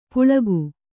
♪ puḷagu